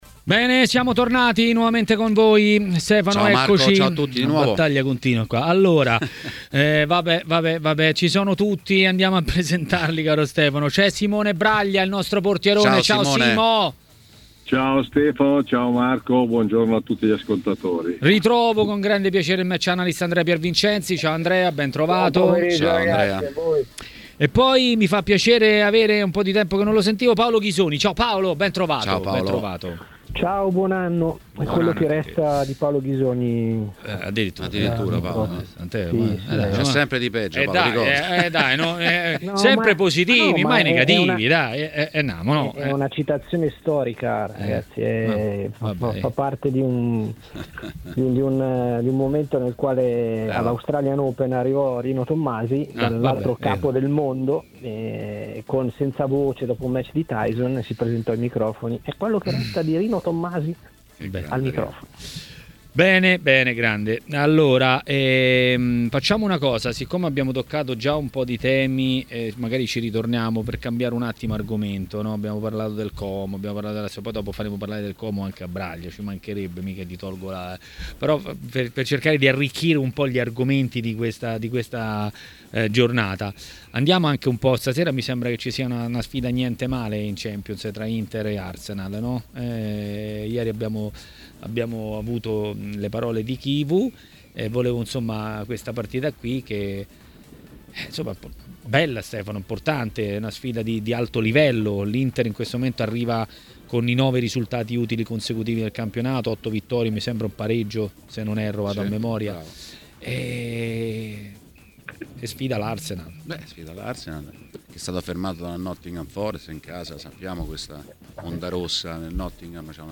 Ospite di Maracanà, trasmissione di TMW Radio, è stato l'ex portiere Simone Braglia.